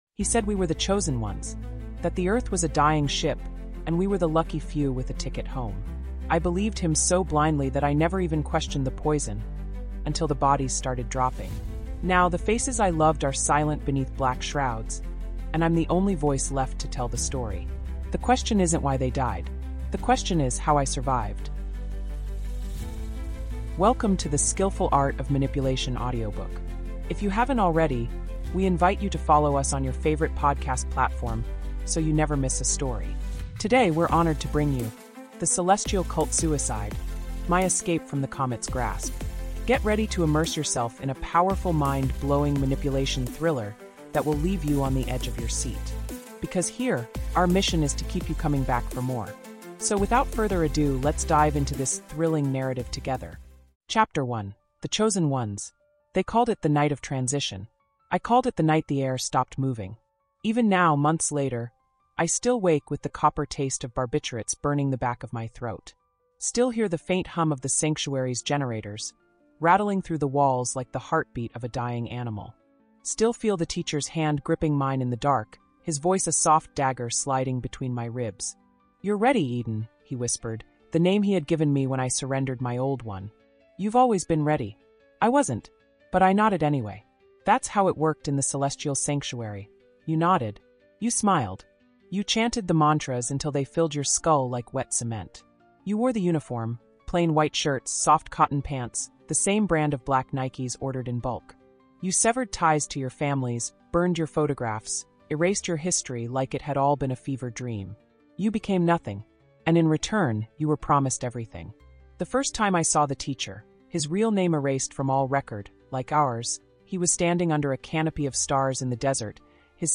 The Celestial Cult Suicide: My Escape from the Comet’s Grasp | Audiobook